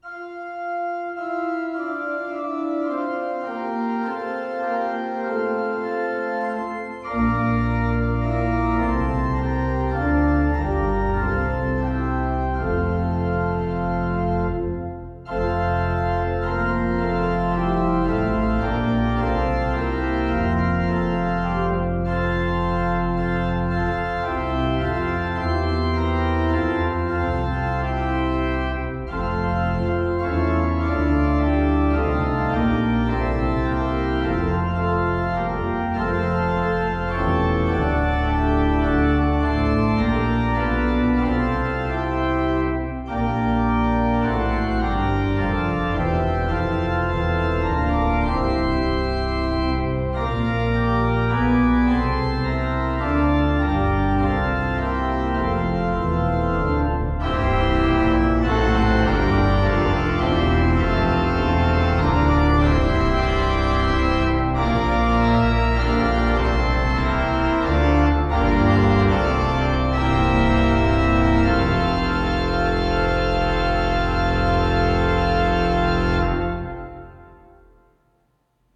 hymn
organ